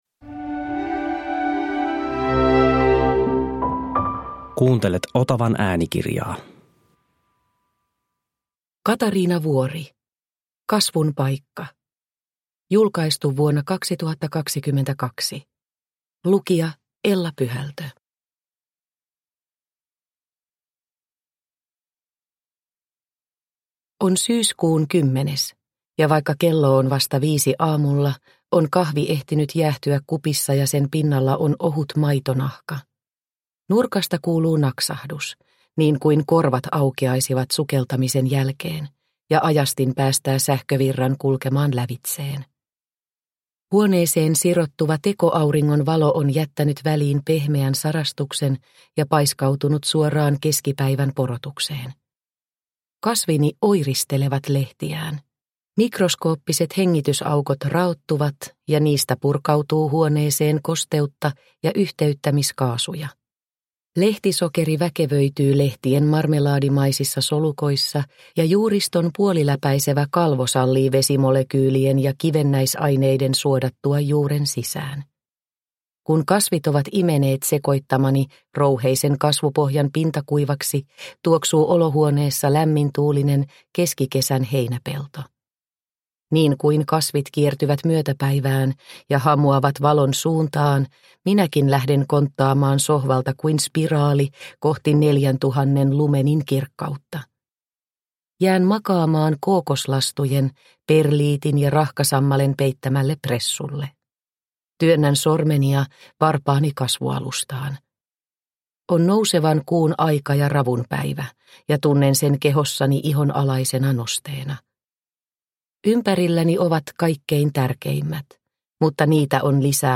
Kasvun paikka – Ljudbok